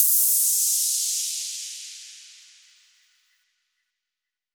VTDS2 Song Kit 11 Rap 1 Out Of 2 FX Hit Noiz.wav